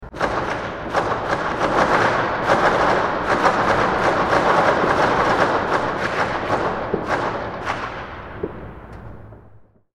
Small-Scale Pyrotechnic Explosions Sound Effect
A crisp, high-definition recording of a rapid firecracker sequence. Features sharp, staccato pops with natural urban reverberation, capturing the authentic sound of small street-grade fireworks or a string of firecrackers.
Small-scale-pyrotechnic-explosions-sound-effect.mp3